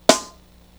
RIM1.WAV